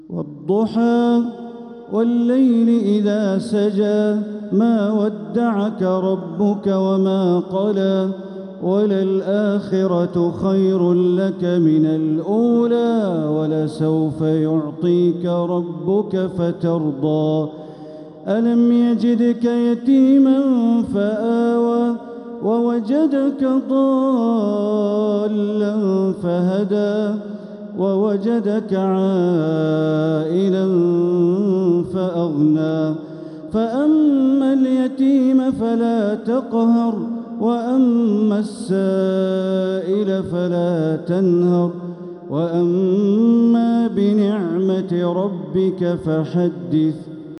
سورة الضحى | مصحف تراويح الحرم المكي عام 1446هـ > مصحف تراويح الحرم المكي عام 1446هـ > المصحف - تلاوات الحرمين